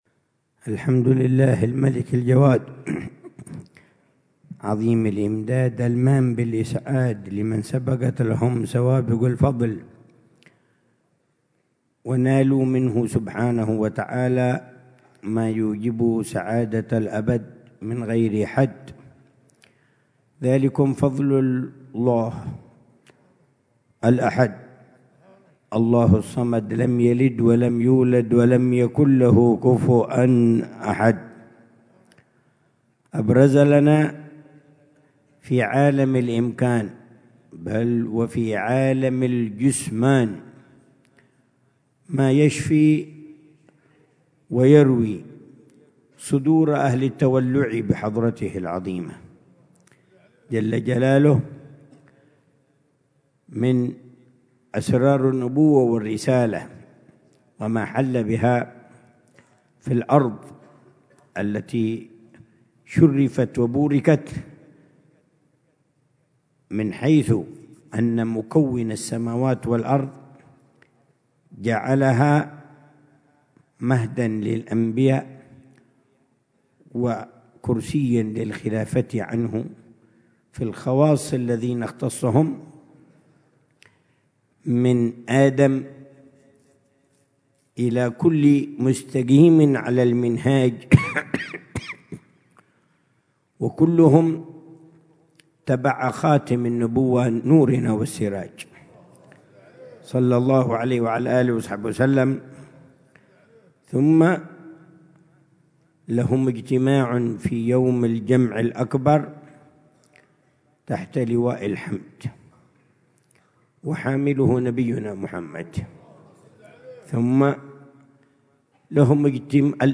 محاضرة العلامة الحبيب عمر بن محمد بن حفيظ ضمن سلسلة إرشادات السلوك في دار المصطفى، ليلة الجمعة 12 جمادى الآخرة 1446هـ، بعنوان: